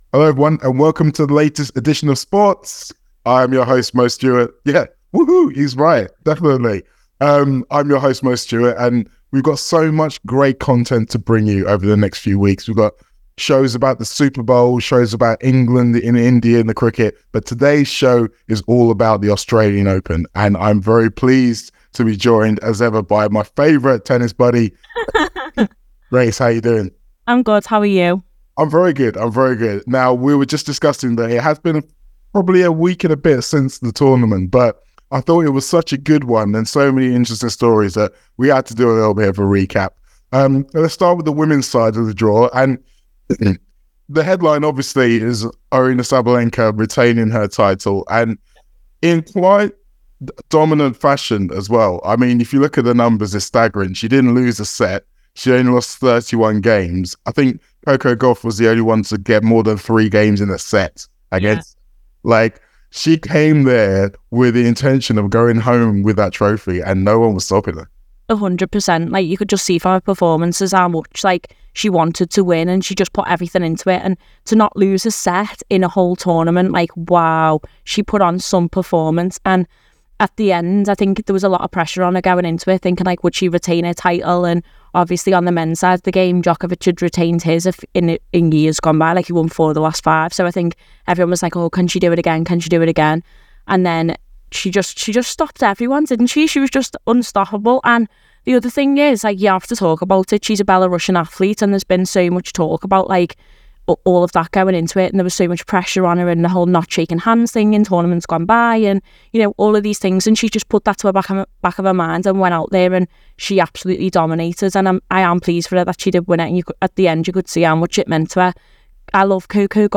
Below is a clip from the show – subscribe for more on the Australian Open and Andy Murray’s future…